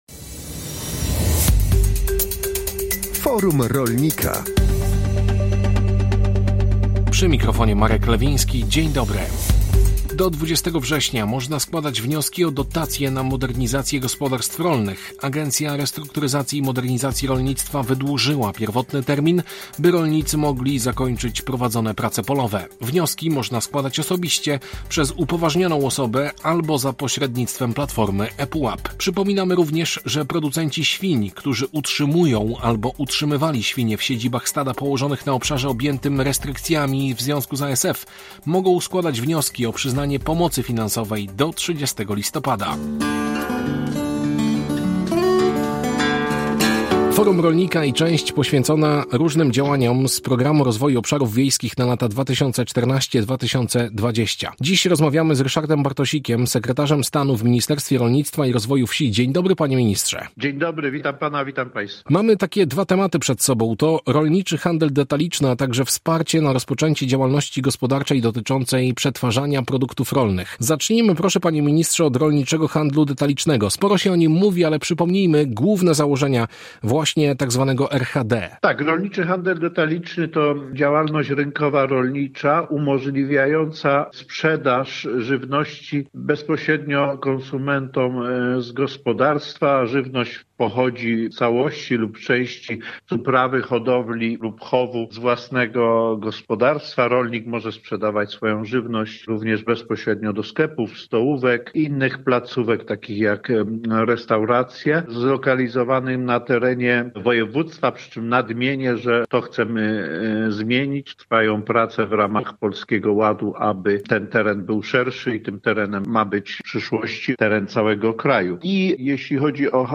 Forum Rolnika to audycja skierowana do polskich rolników.